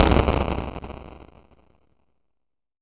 EXPLOSION2-S.WAV